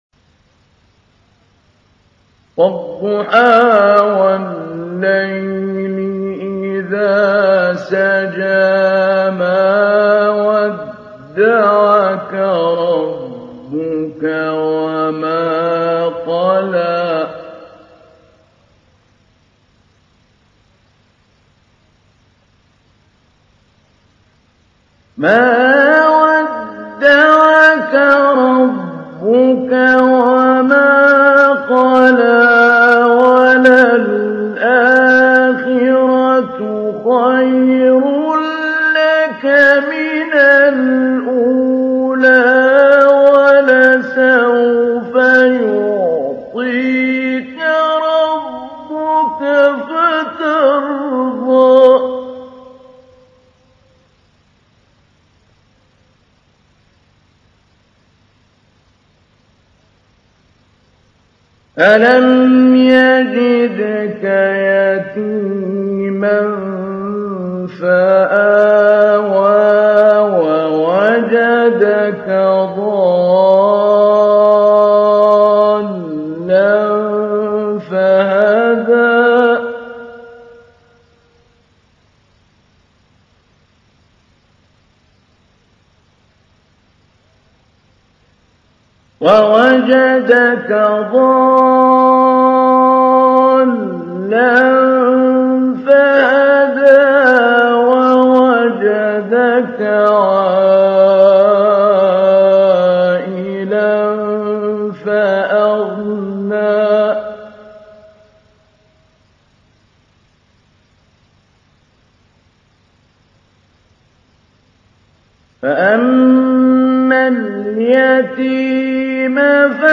تحميل : 93. سورة الضحى / القارئ محمود علي البنا / القرآن الكريم / موقع يا حسين